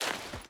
Dirt Jump.ogg